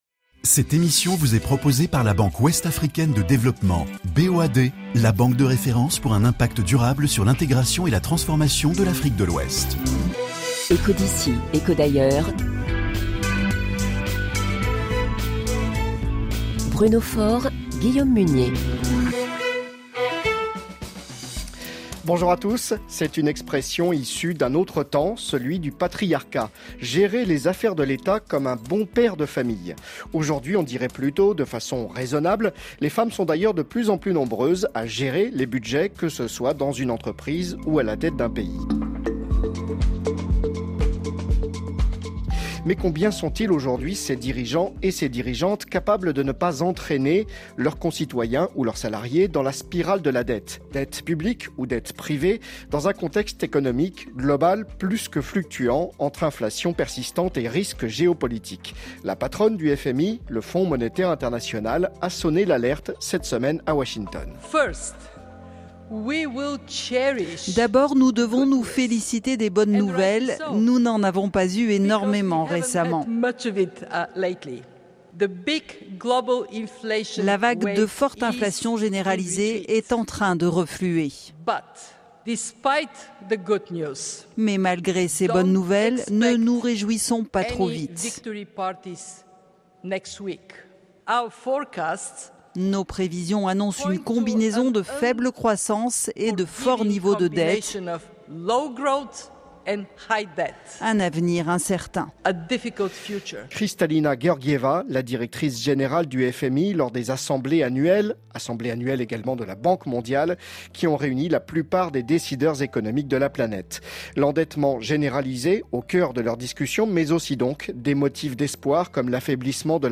Nous en parlons avec deux invités prestigieux en ligne de Washington où ils participaient aux assemblées annuelles du FMI et de la Banque Mondiale.